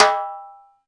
086 - Timbales.wav